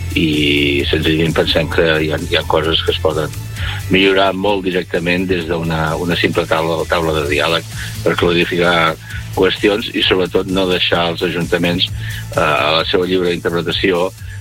Jordi Soler, l’alcalde de Calonge i Sant Antoni va explicar al Supermatí el punt de vista dels alcaldes del Baix Empordà que reclamen aclariments en el Pla Director Urbanístic. Soler diu que el problema d’interpretació de la normativa d’intervenció paisatgística no es pot resoldre amb una circular i celebra que Calvet convidi a fer diàleg i espera que els hi posin “dia i hora”.